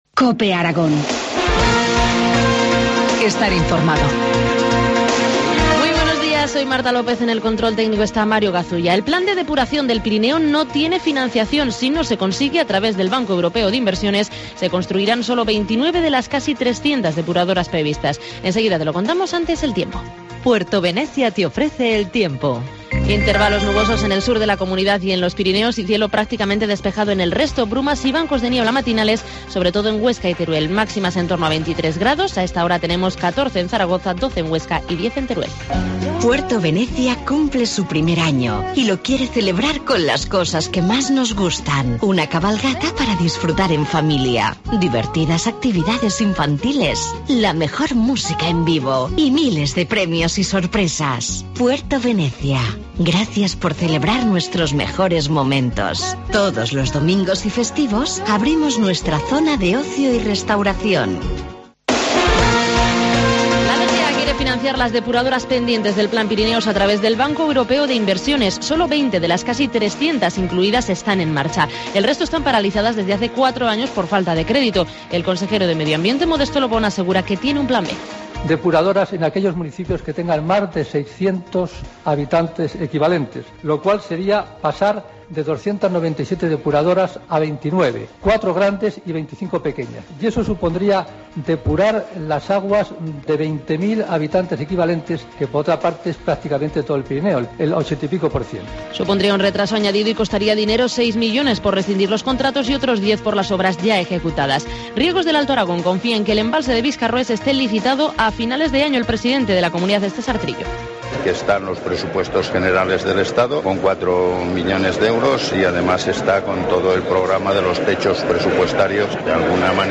Informativo matinal, miércoles 9 de octubre, 8.25 horas